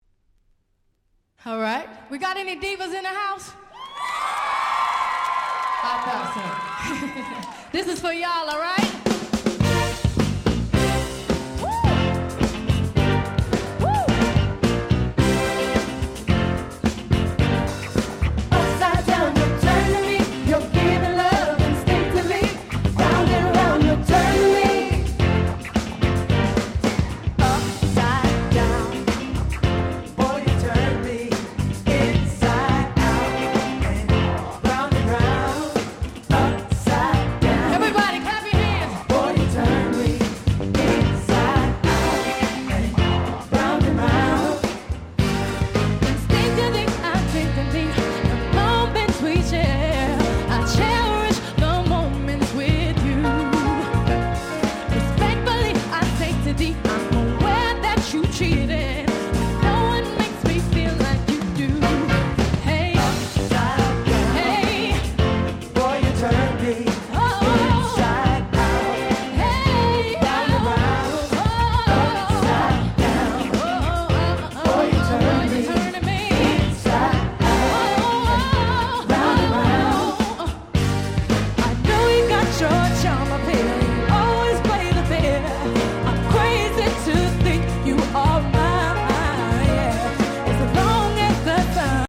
00' Super Hit R&B !!